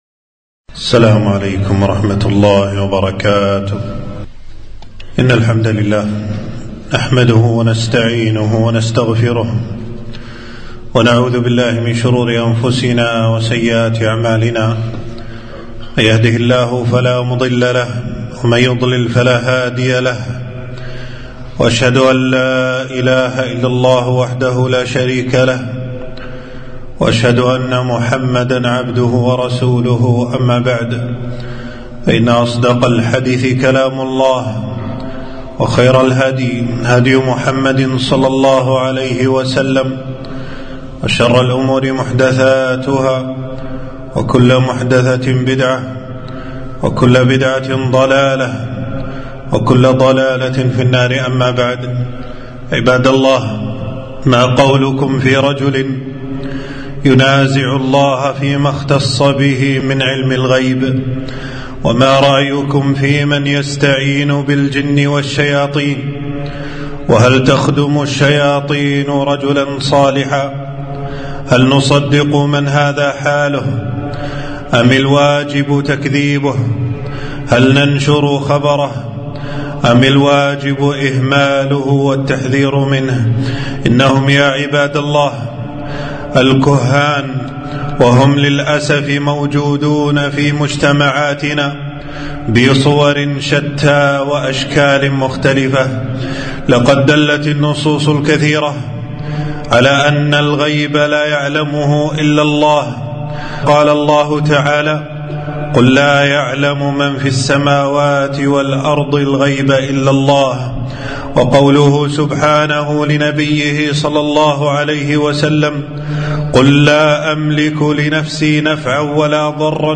خطبة - خطر الكهانة والحذر من الكهان